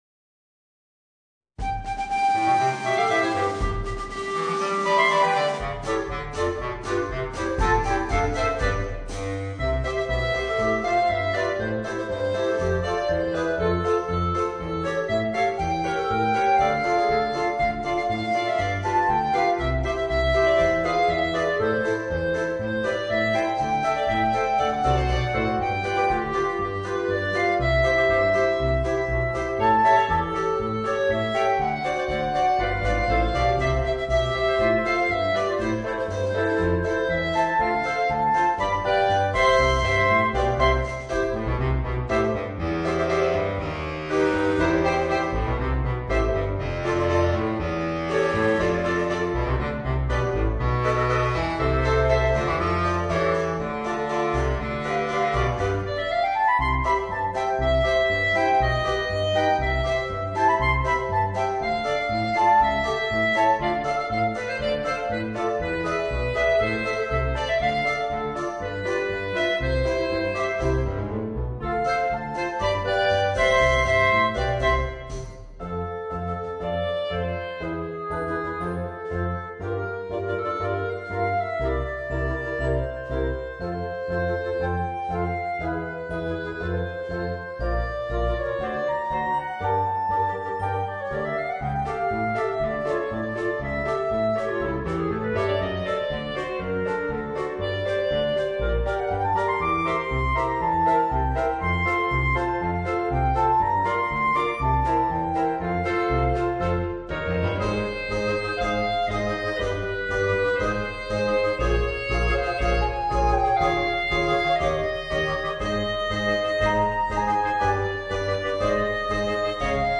Voicing: 4 Clarinets and Piano